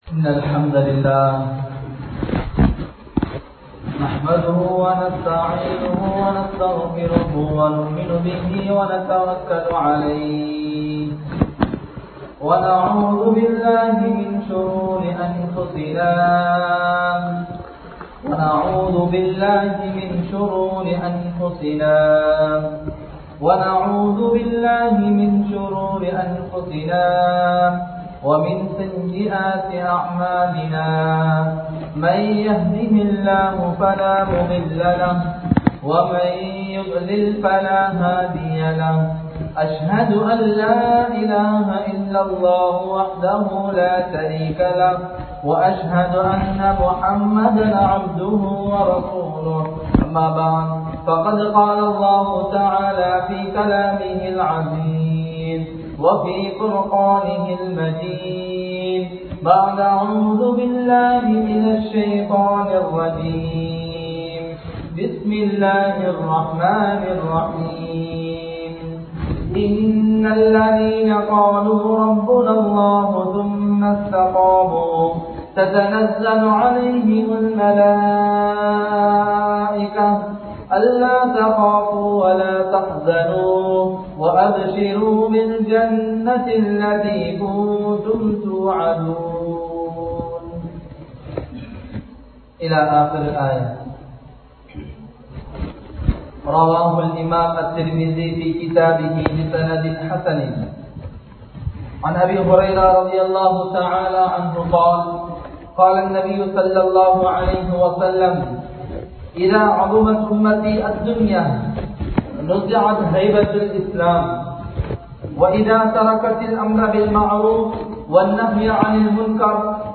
பிள்ளை வளர்ப்பு (Well growth of children) | Audio Bayans | All Ceylon Muslim Youth Community | Addalaichenai
DanGolla Jumua Masjith